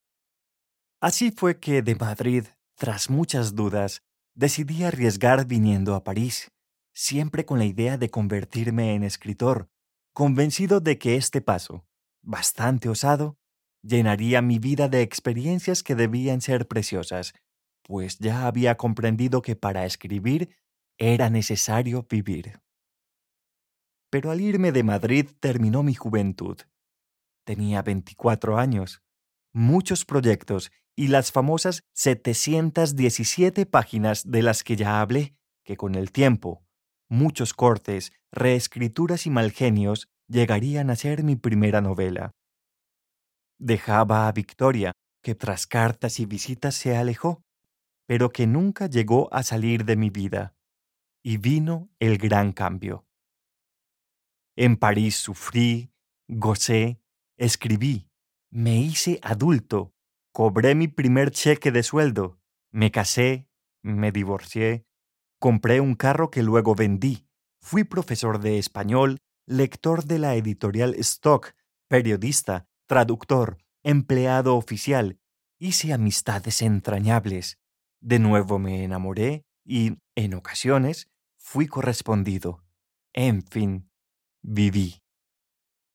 Fragmentos de audiolibros